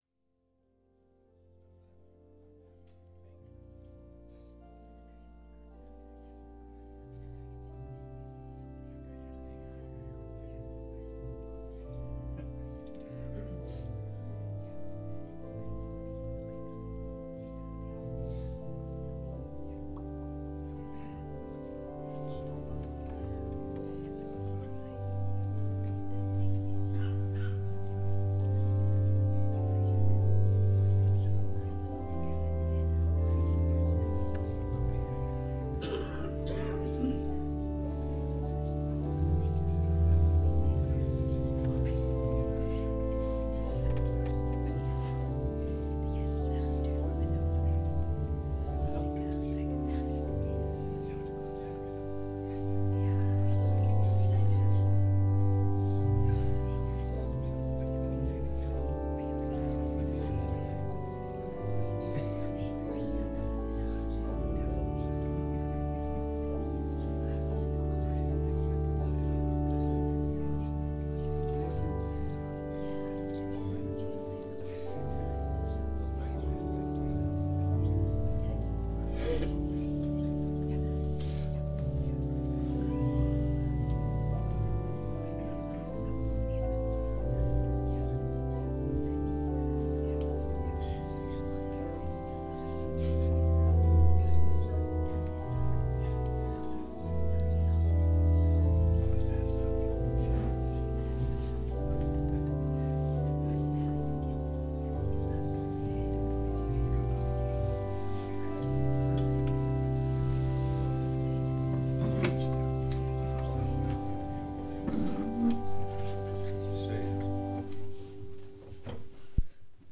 Locatie: Vrij Evangelisatie 'Het Mosterdzaadje' Gortel